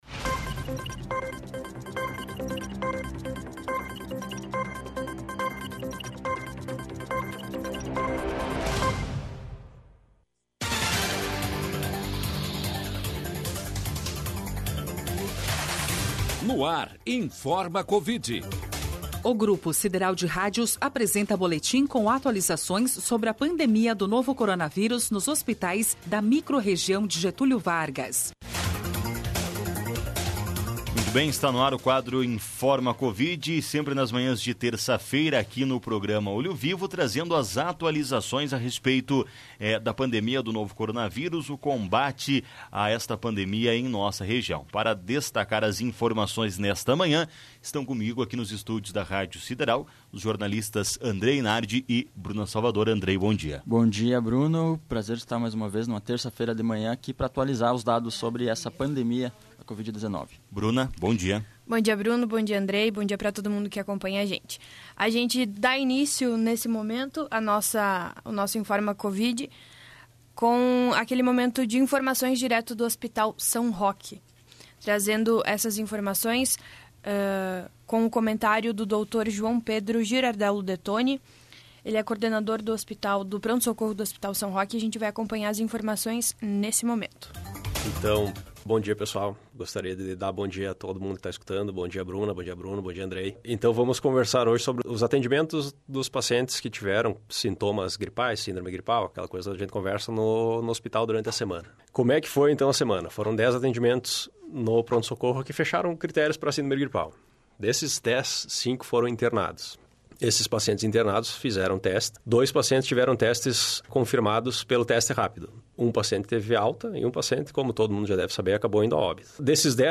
O boletim é um informativo com atualizações acerca da pandemia do novo coronavírus na microrregião de Getúlio Vargas.